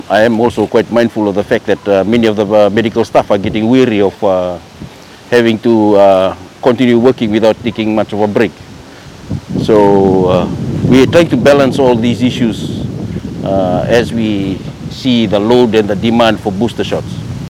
Stały sekretarz ds. zdrowia, dr James Fung w Albert Park dzisiaj